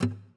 ui_aceptar.wav